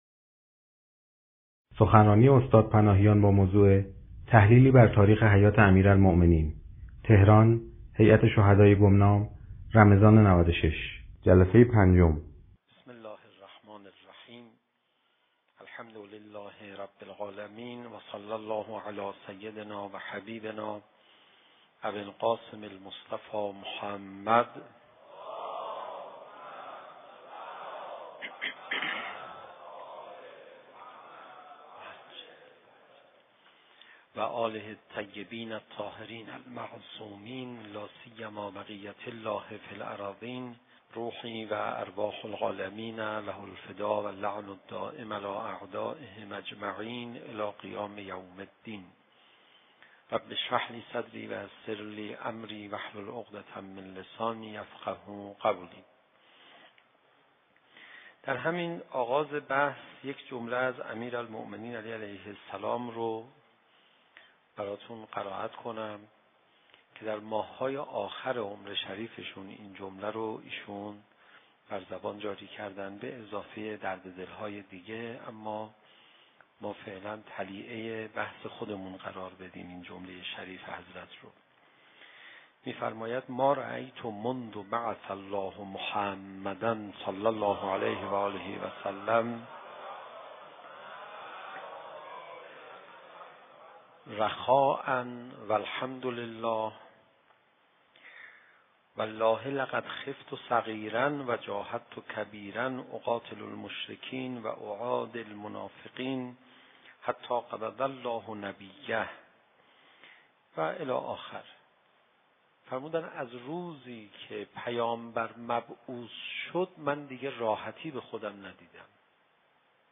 شب پنجم ماه رمضان_هیئت شهدای گمنام _تحلیلی بر تاریخ حیات امیرالمؤمنین(علیه السلام)
سخنرانی